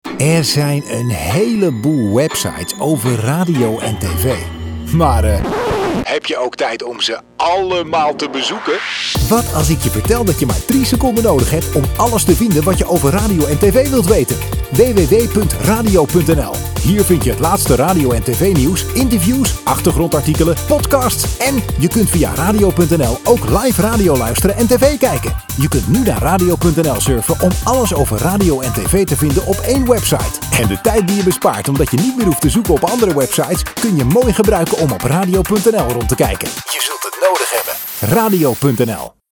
Oude commercials
deze commercial honderden keren te horen op City FM.